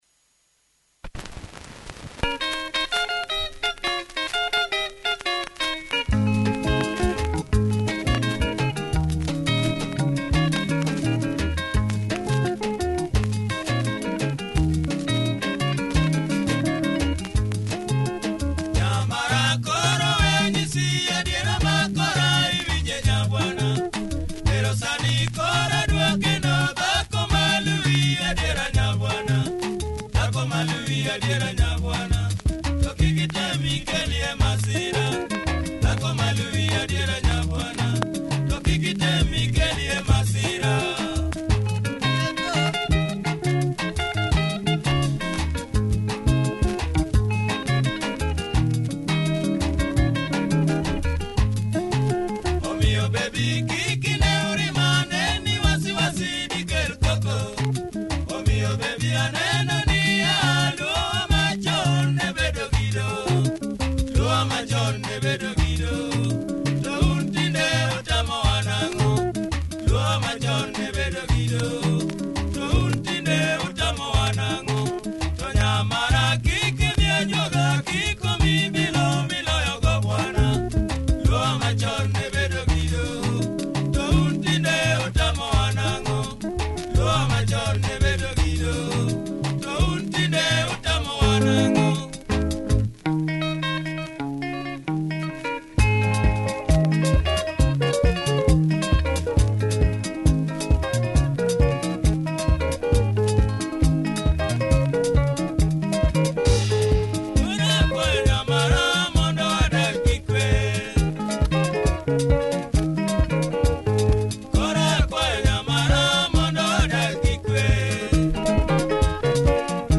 Nice drive in this track, great group! https